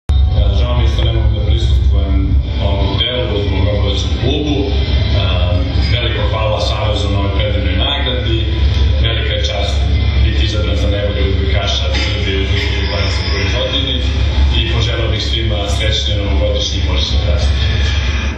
OSS – NOVOGODIŠNJI KOKTEL
Izjava Srećka Lisinca